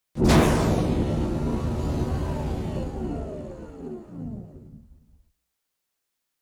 phaseOpen.wav